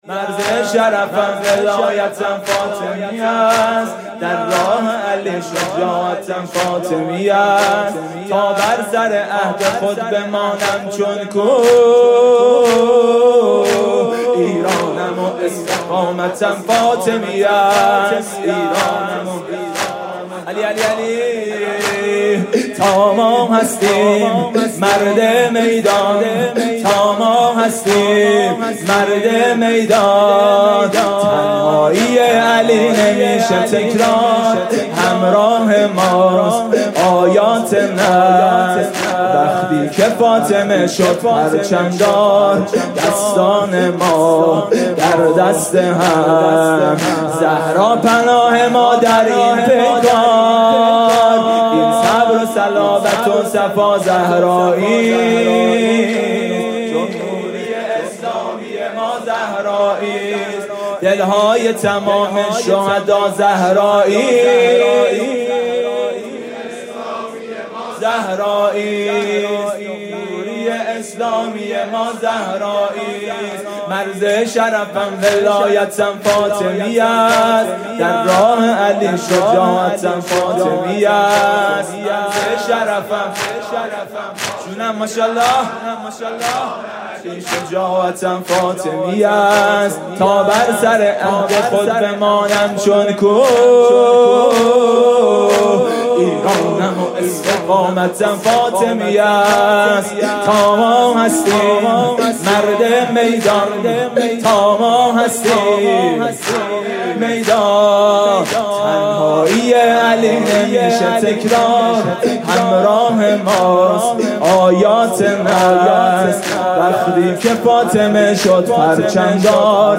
شب پنجم فاطمیه اول ۱۴۰۴ | هیأت میثاق با شهدا
music-icon شور